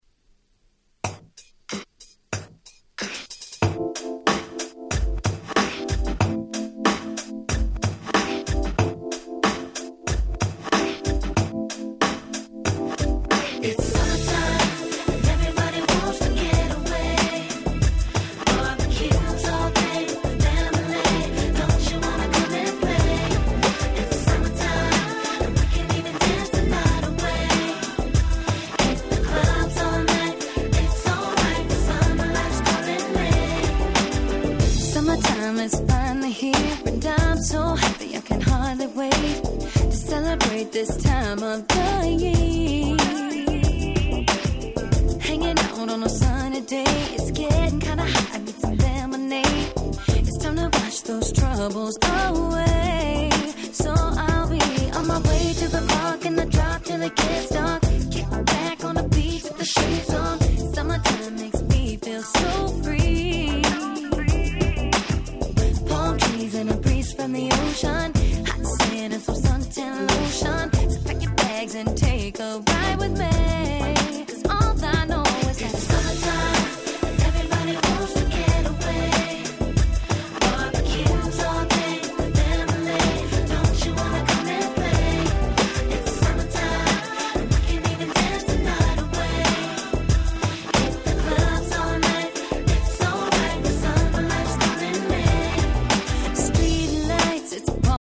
硬質なビートを加えて大変使い易く、踊れるRemixに仕上がっております。